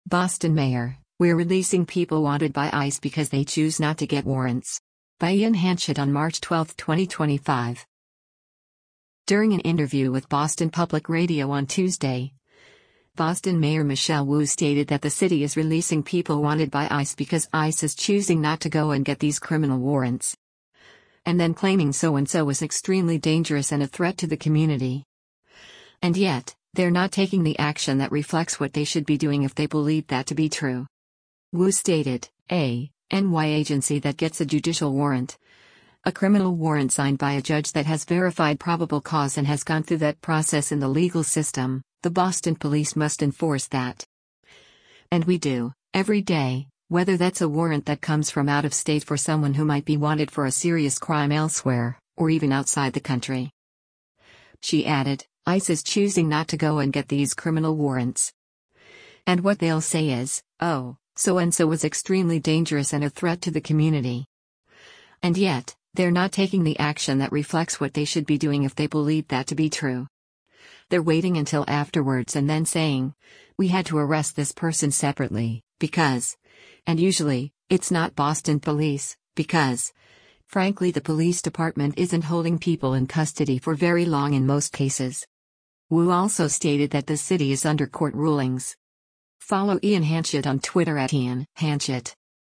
During an interview with Boston Public Radio on Tuesday, Boston Mayor Michelle Wu stated that the city is releasing people wanted by ICE because “ICE is choosing not to go and get these criminal warrants.”